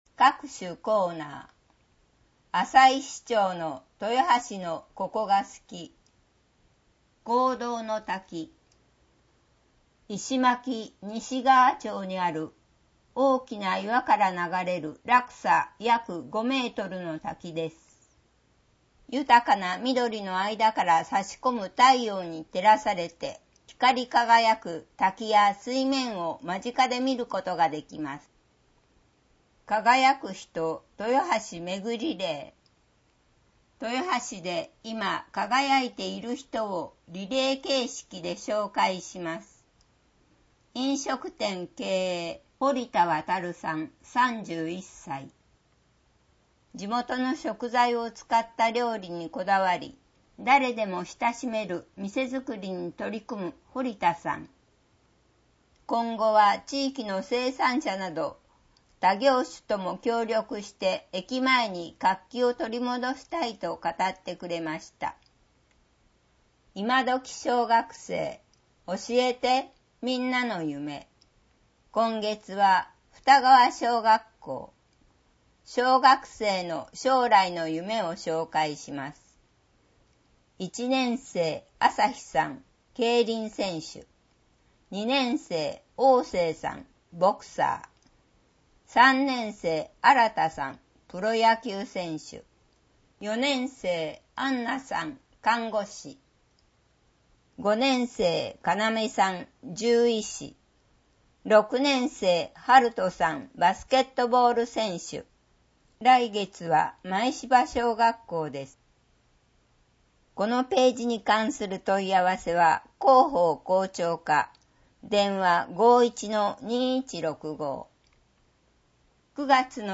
• 「広報とよはし」から一部の記事を音声でご案内しています。視覚障害者向けに一部読み替えています。
• 音声ファイルは一定期間を過ぎると削除します。（音声ファイルは『音訳グループぴっち』提供）